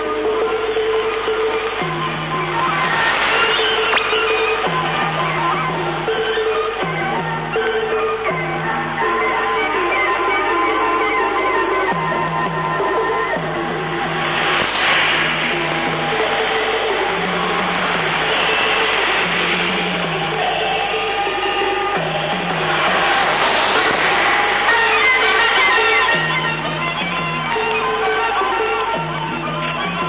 Китайская глушилка "Chinese Firedeagon" на частоте 14600 кГц АМ.